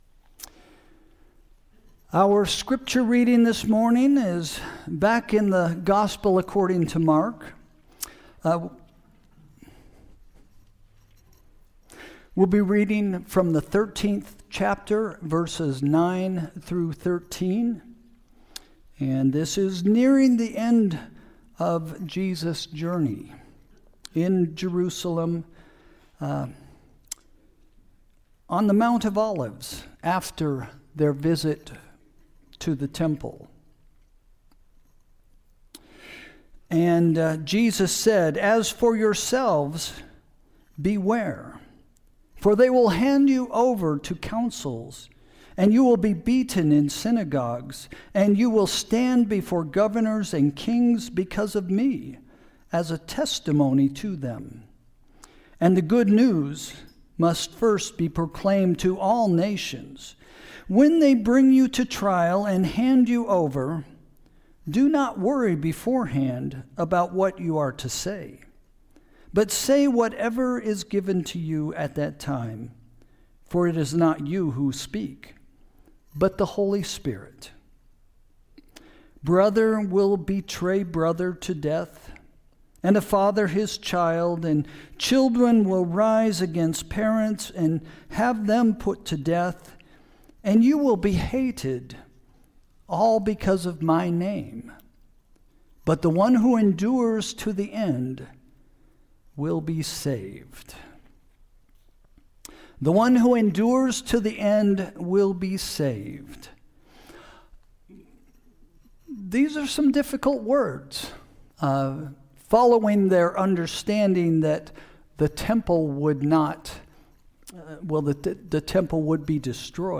Sermon – February 22, 2026 – “A Faith Conviction”